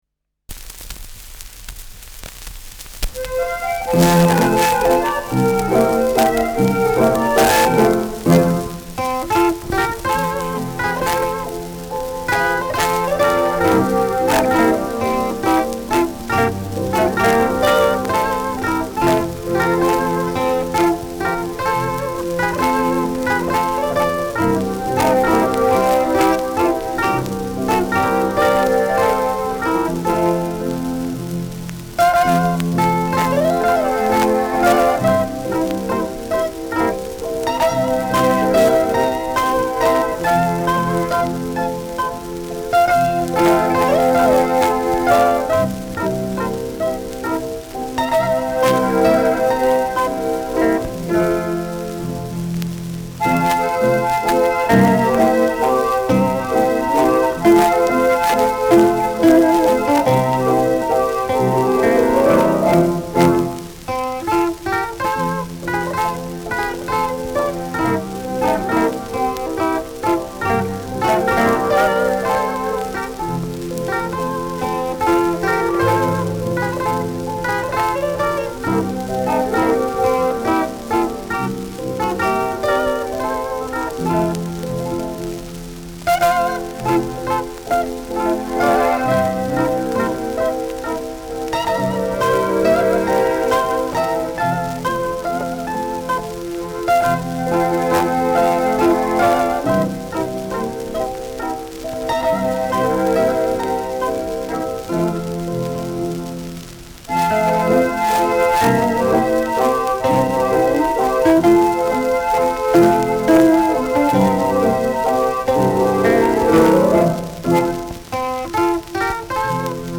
Schellackplatte
präsentes Knistern : leichtes Rauschen : leichtes „Schnarren“
[Berlin] (Aufnahmeort)